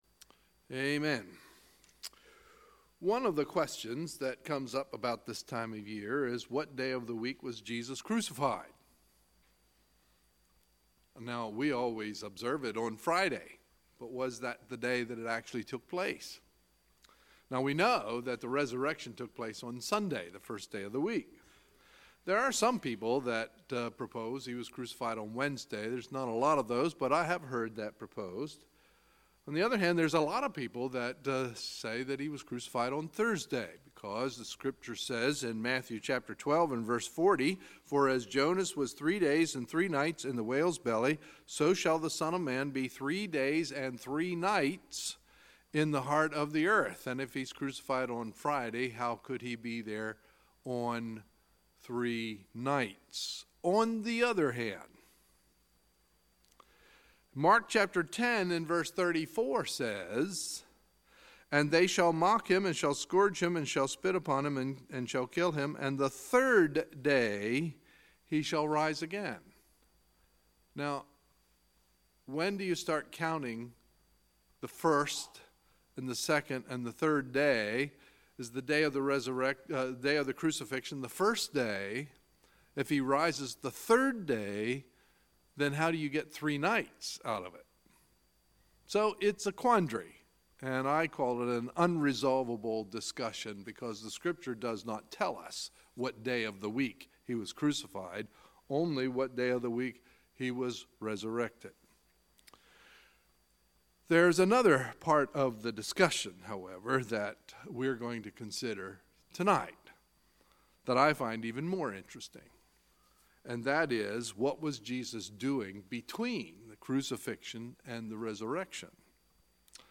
Sunday, April 1, 2018 – Sunday Evening Service